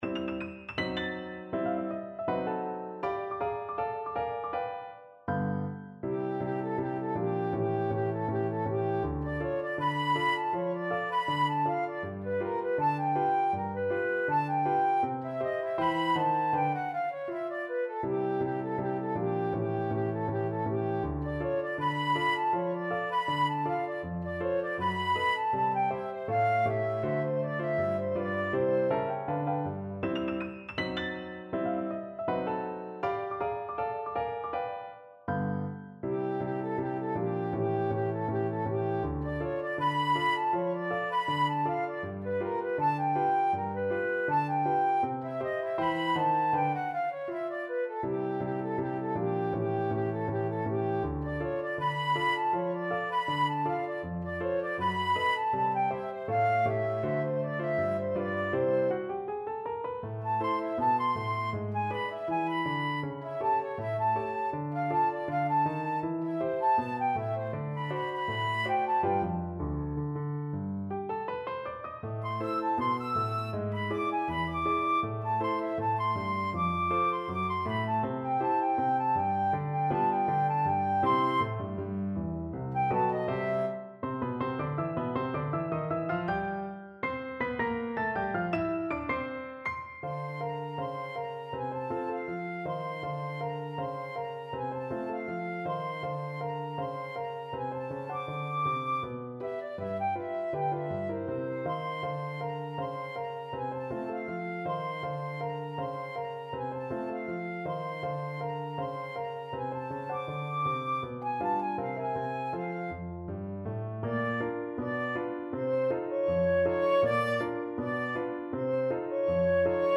Jazz
Flute version
2/2 (View more 2/2 Music)
G5-E7